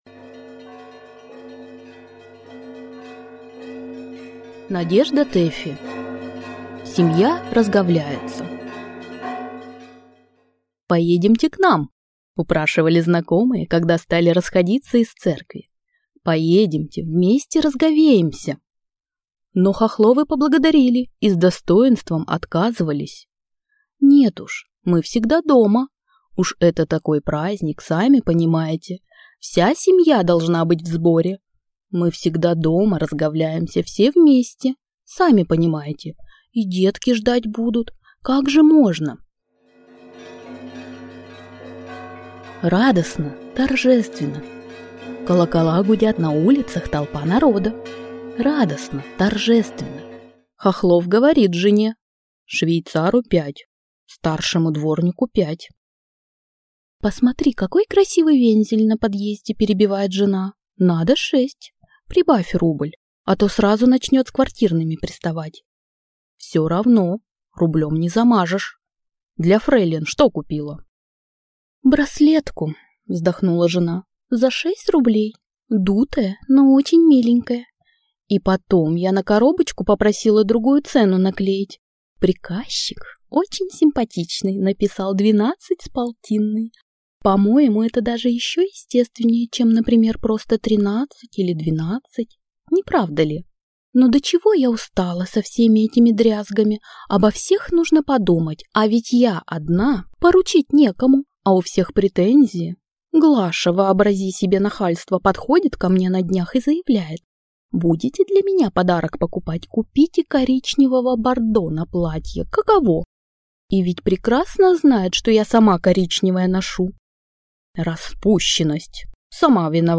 Аудиокнига Семья разговляется | Библиотека аудиокниг
Aудиокнига Семья разговляется Автор Надежда Тэффи